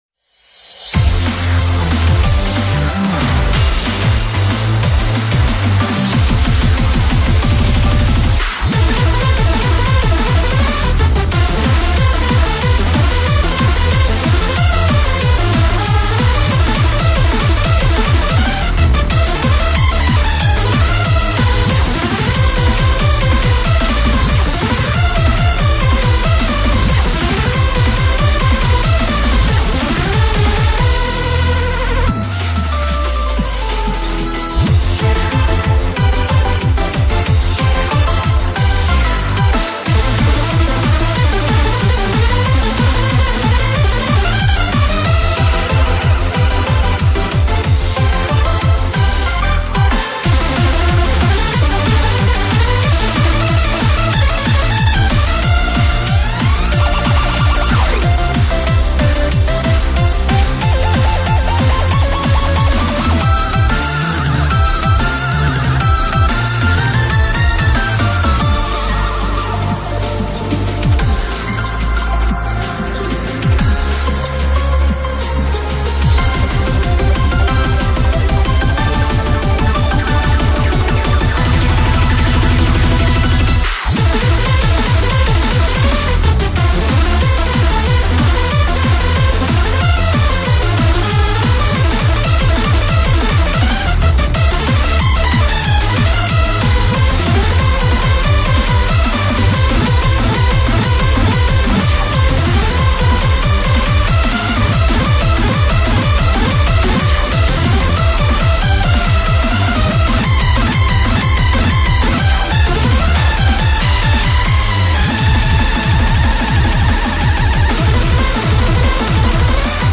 BPM93-370
Audio QualityPerfect (Low Quality)